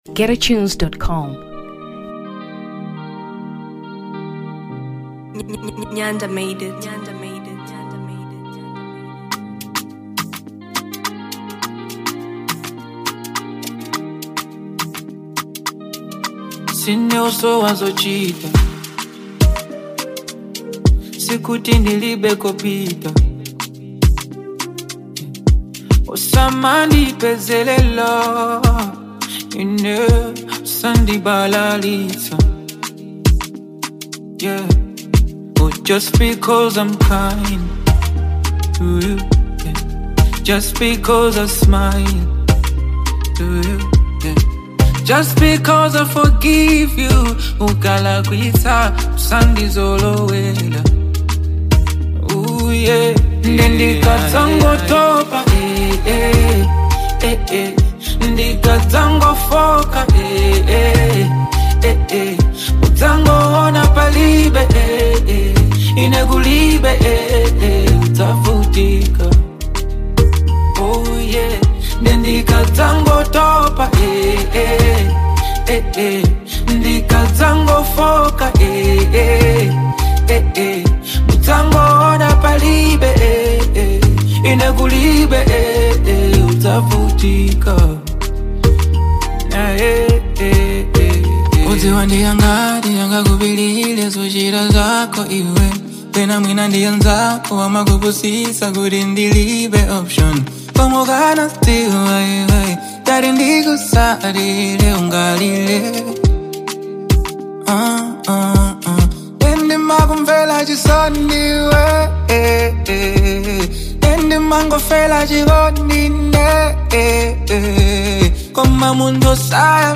Afro 2023 Malawi